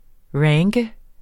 Udtale [ ˈɹaŋgə ]